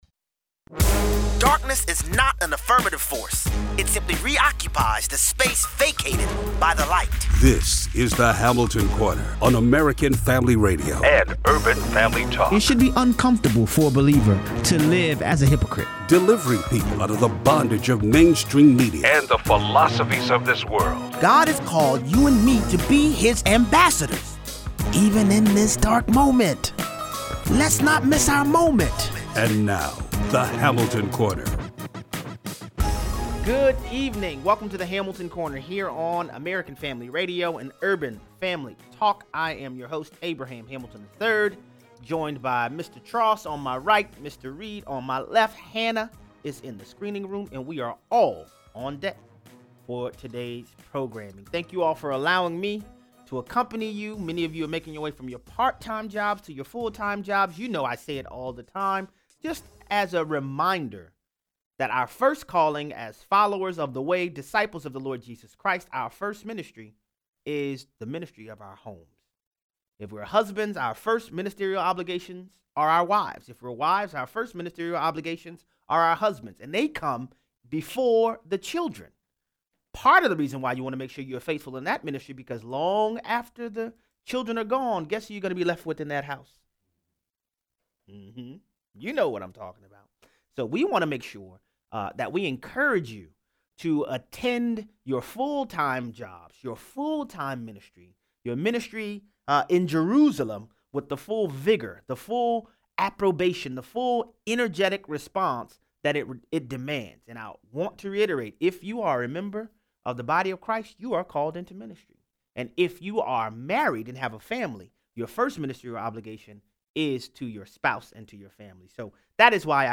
High minimum wages are actually resulting in the elimination entry level jobs. 0:43 - 0:60: The Icon and THINX company sends diapers, bottled water and protein bars to female Senators so they can fight Brett Kavanaugh’s SCOTUS nomination late into the night. Callers weigh in.